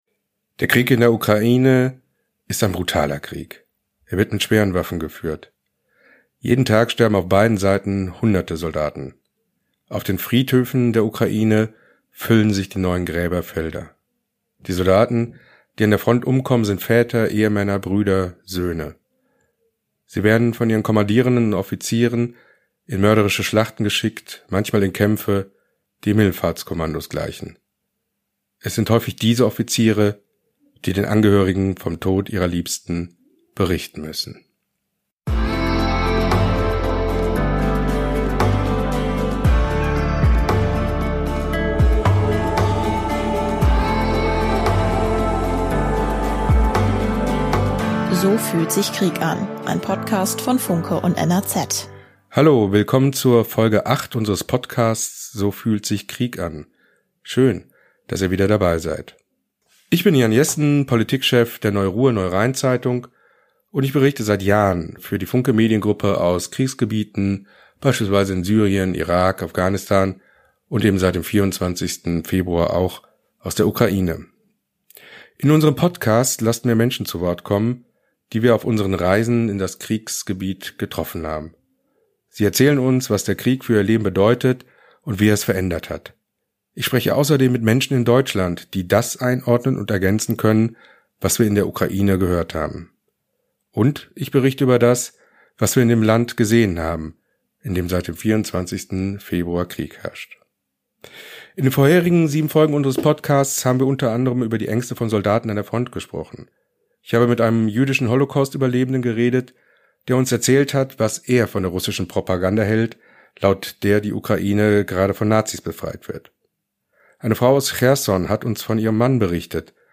mit zwei ukrainischen Offizieren
mit einem Bundeswehr-Offizier